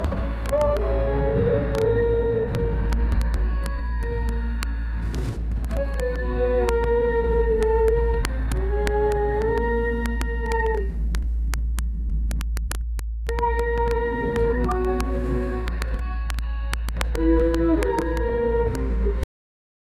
There should be the sounds of the dial in the background, the atmosphere is gloomy, mourning, in the spirit of an authoritarian state. The sound should be cyclical, completed so that it can continue again without breaks. 0:20 Created Apr 14, 2025 3:19 PM